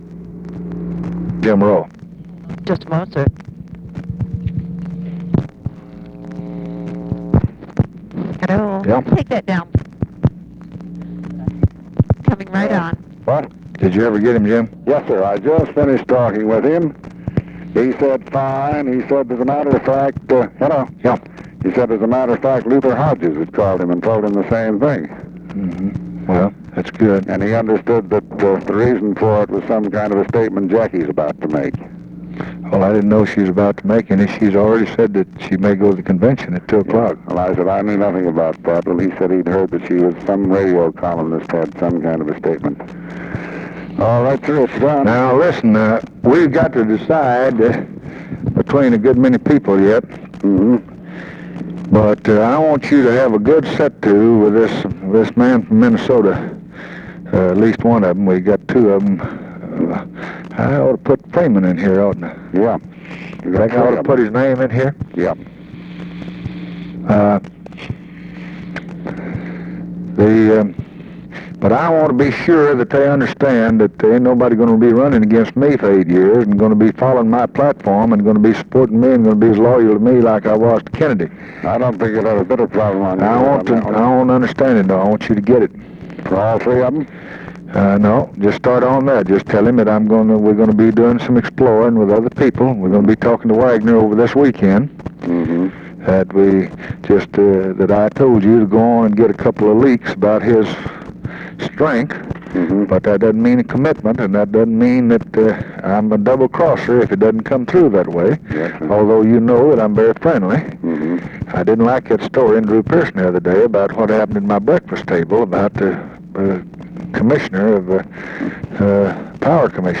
Conversation with JAMES ROWE, July 30, 1964
Secret White House Tapes